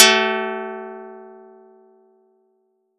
Audacity_pluck_11_14.wav